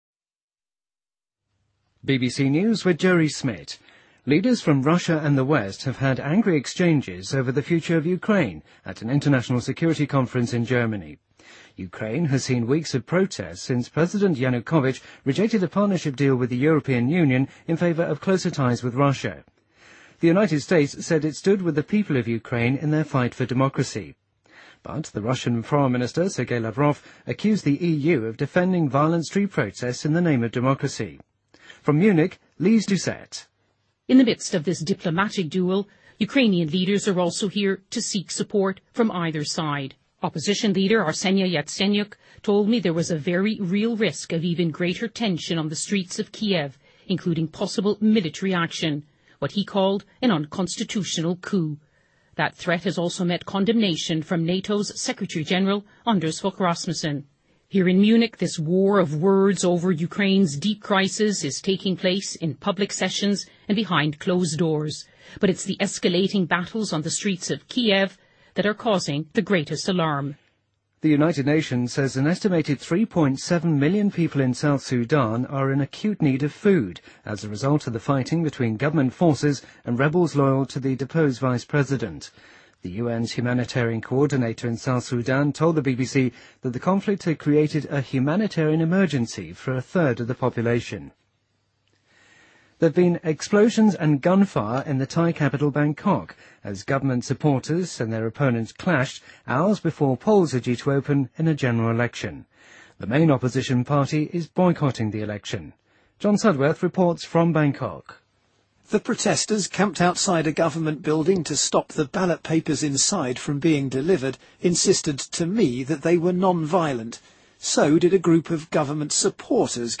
BBC news,2014-02-02